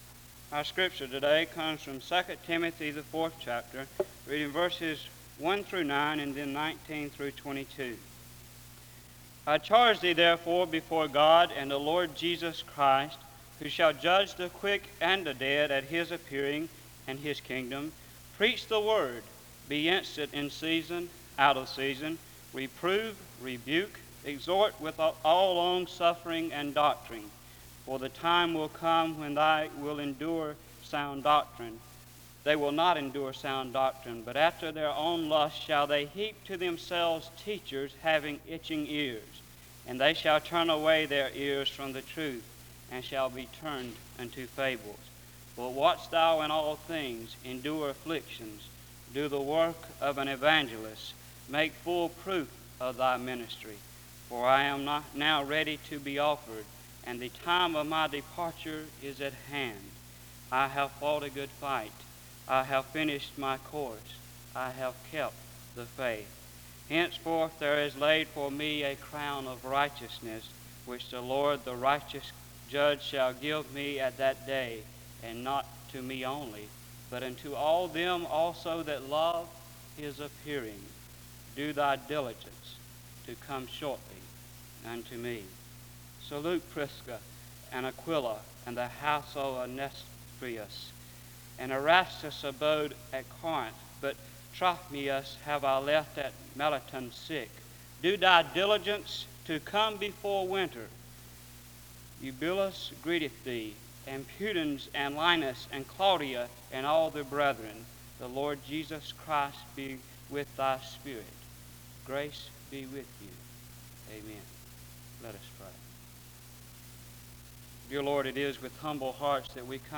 The service begins with a reading of 2 Timothy 4:1-22 (0:00-1:55). A prayer is then offered (1:56-2:44). The service continues with a period of singing (2:45-5:48).